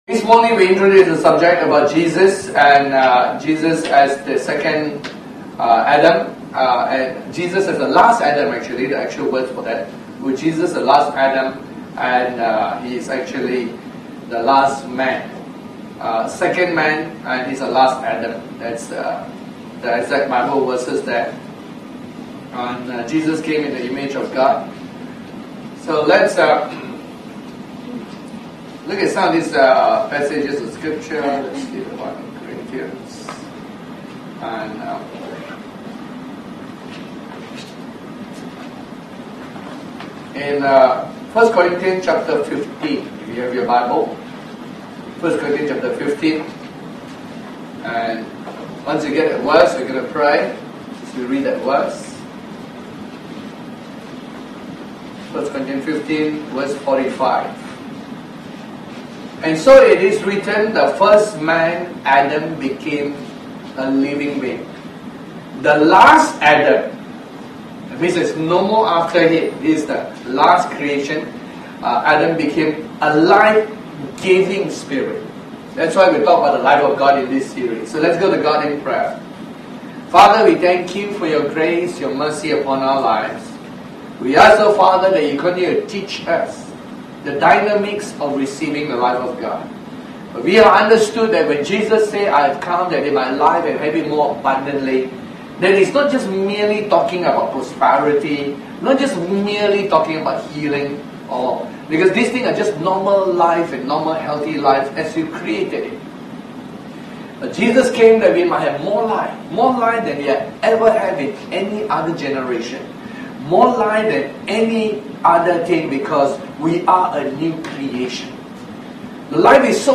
Series: The Life of God Tagged with Sunday Service